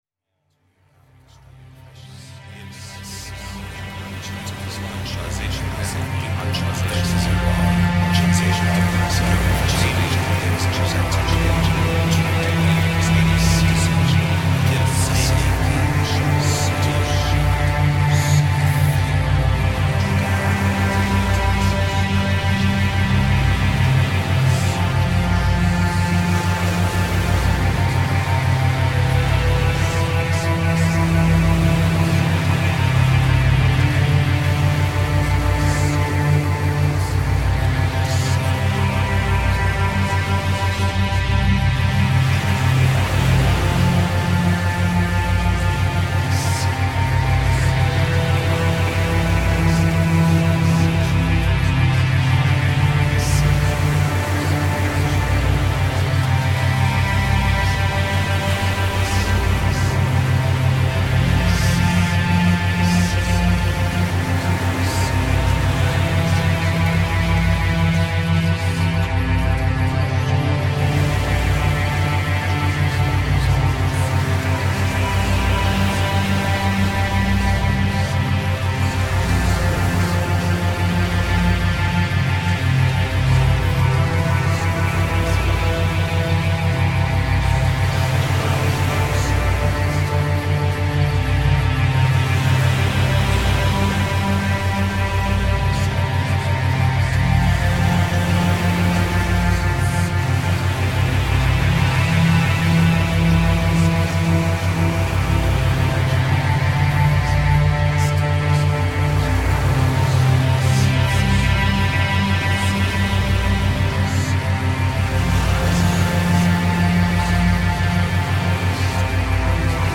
Binaural Beat Frequencies
Isochronic Tones,
Theta Waves
You hear it as a rhythmic pulsating steady low tone.